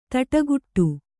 ♪ taṭaguṭṭu